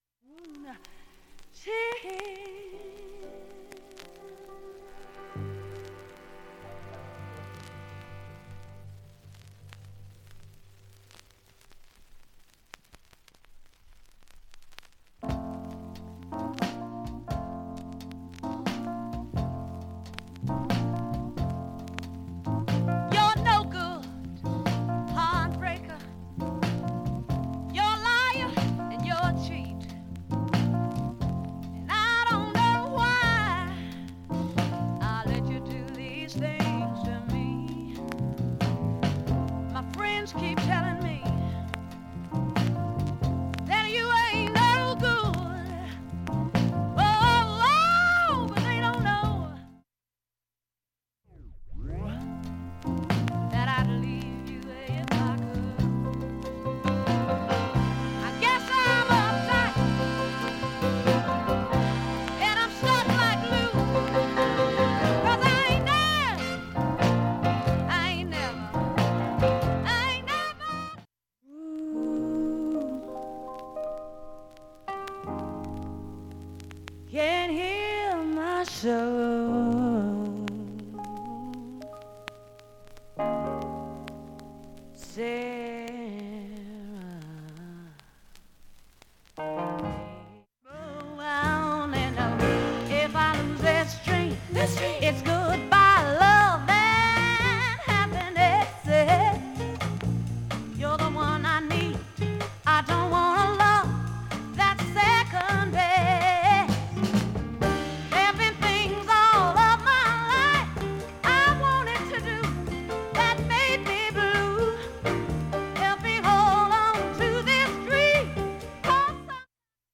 大きいプツなどはありません。
普通に聴けます音質良好全曲試聴済み。